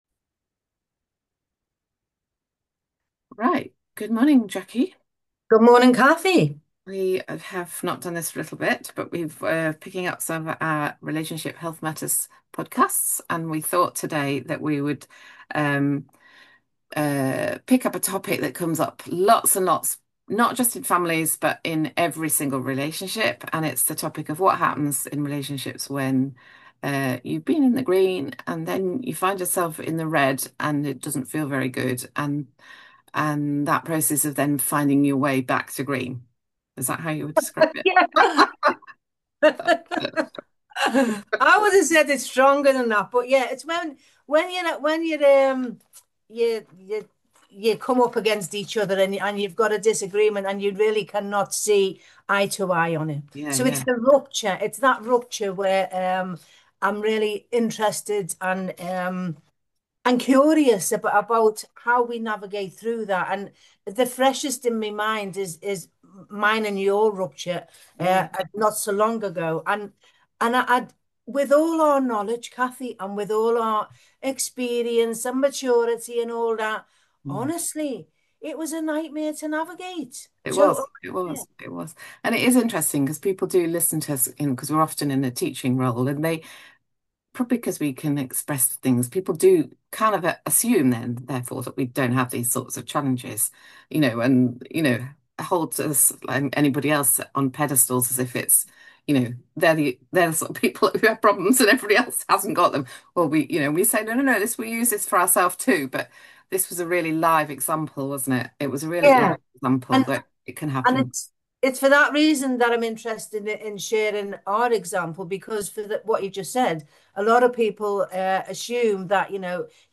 A personal conversation